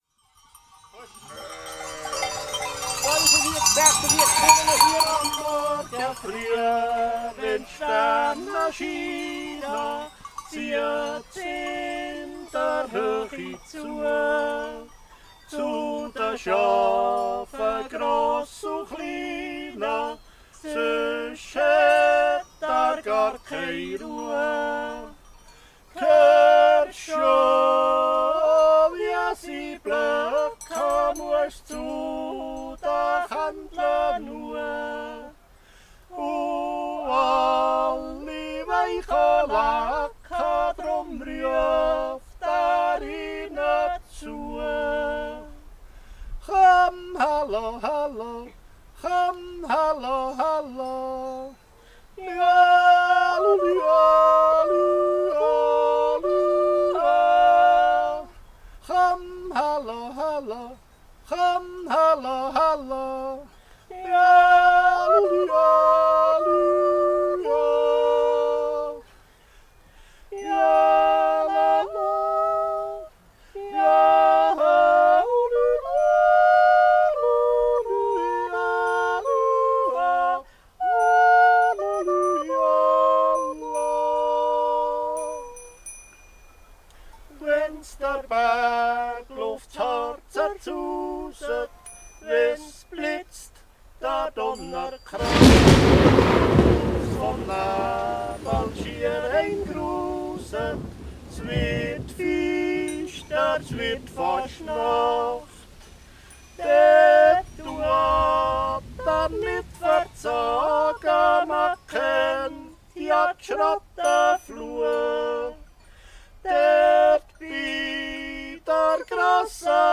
Genre: Folk / Country / Retro / Yodel